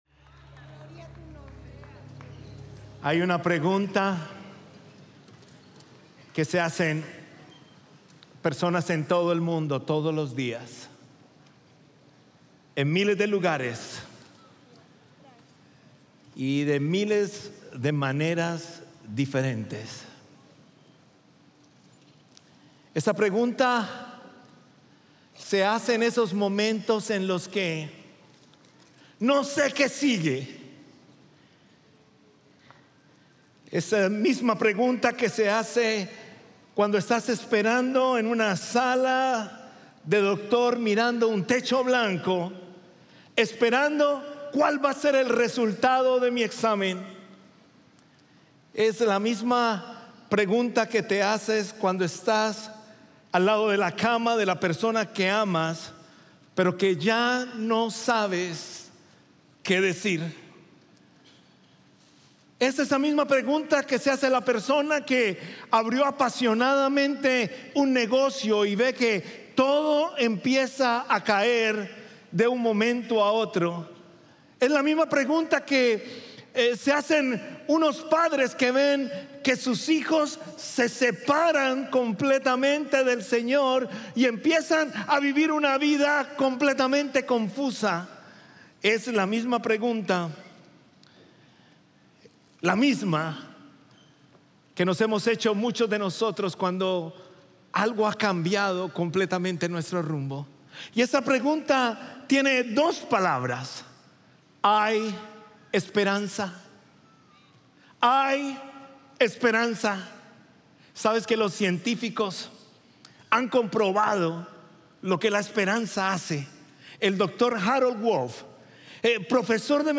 Sermones Conroe – Media Player